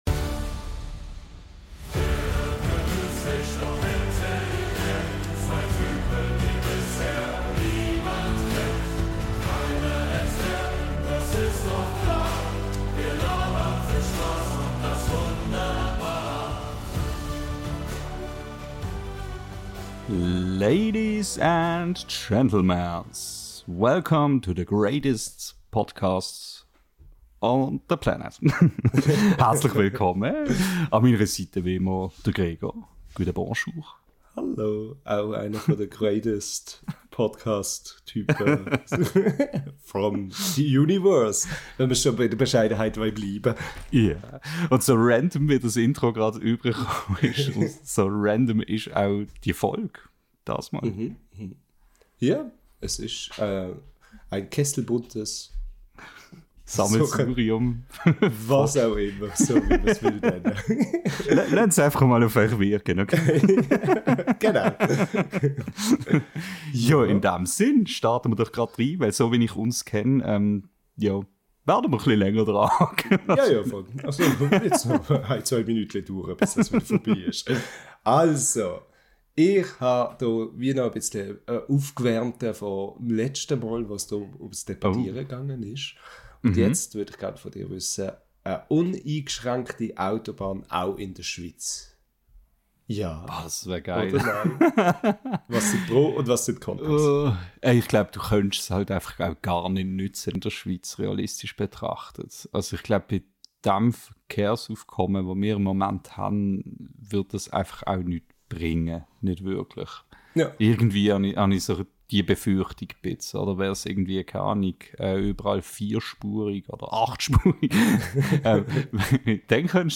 Diese und viele weitere Fragen besprechen wir in der neuen Folge unseres schweizerdeutschen Podcasts.